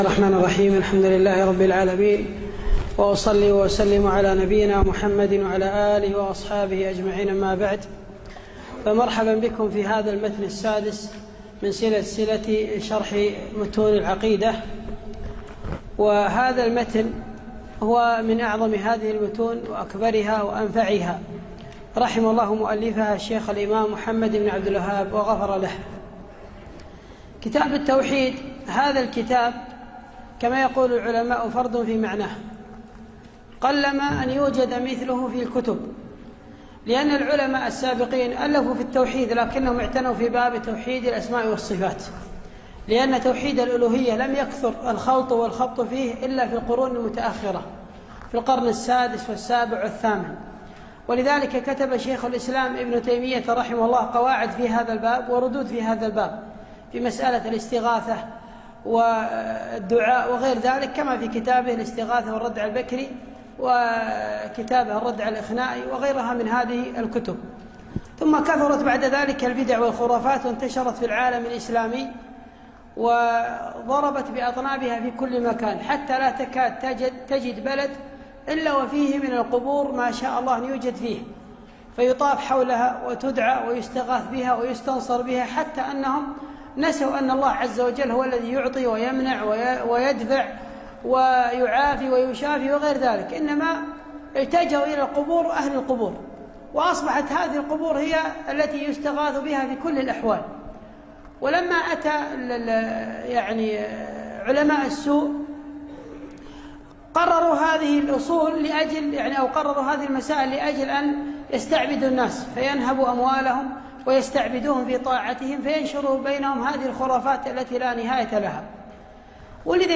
يوم الثلاثاء 28 جمادى الأول 1437هـ الموافق 8 3 2016م في مسجد زيد بن حارثة الجهراء